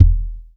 impact_deep_thud_bounce_10.wav